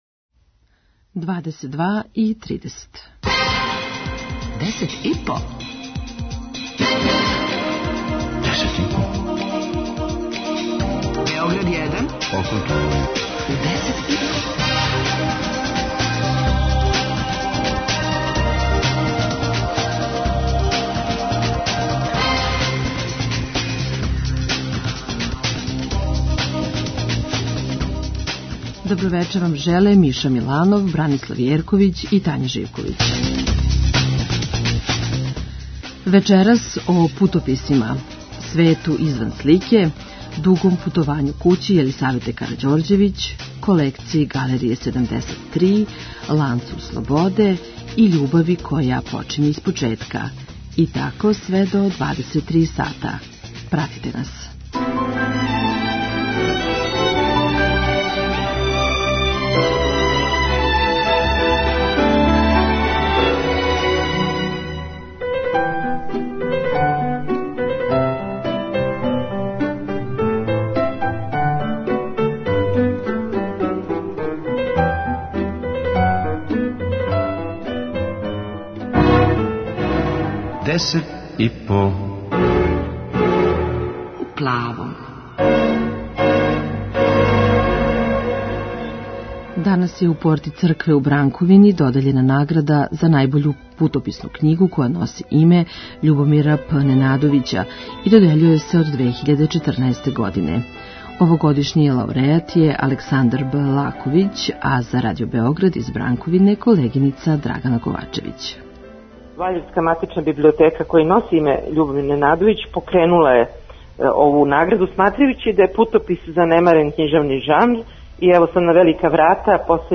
преузми : 5.40 MB Десет и по Autor: Тим аутора Дневни информативни магазин из културе и уметности.